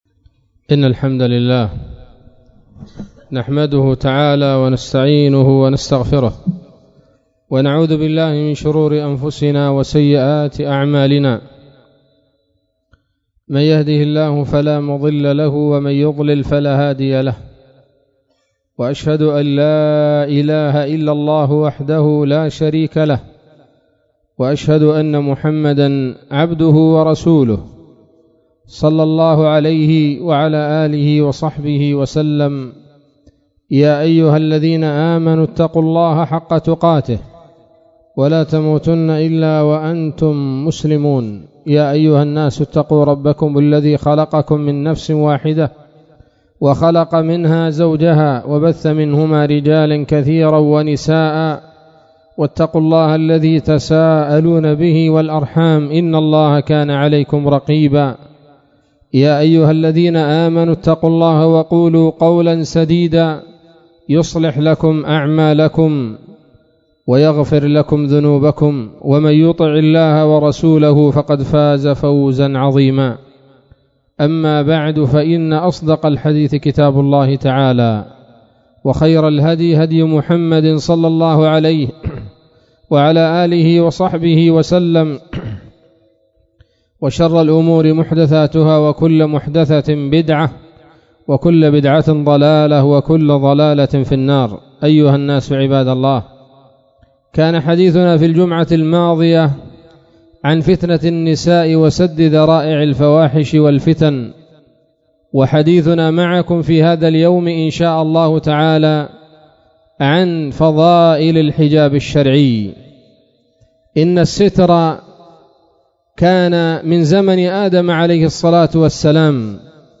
خطبة جمعة بعنوان: (( فضائل الحجاب